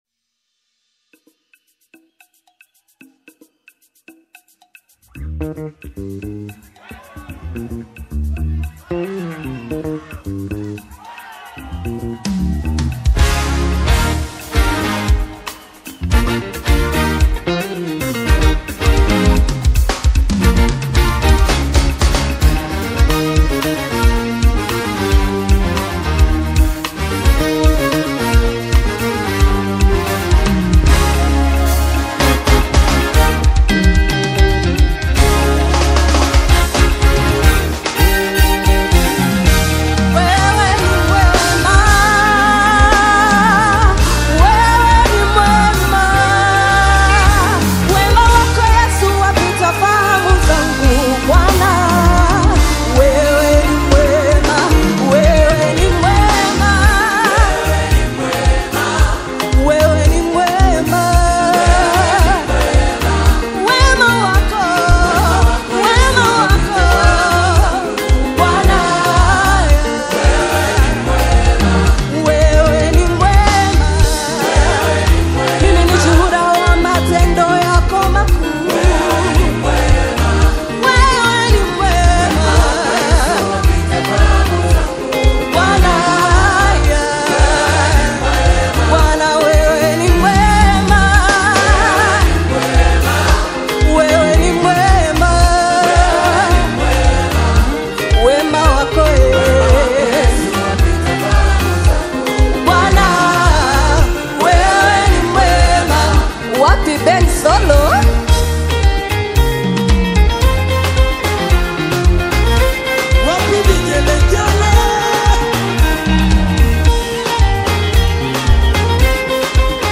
The harmonically lush and rhythmically swaying single